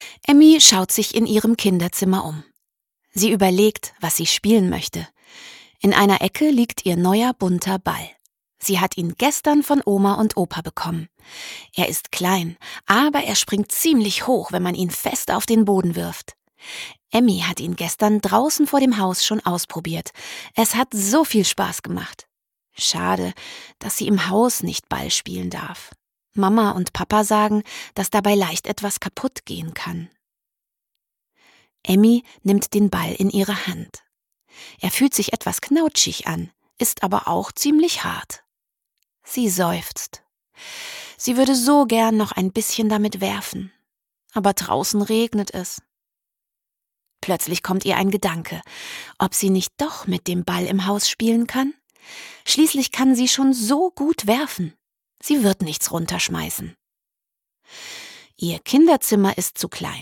Hörbücher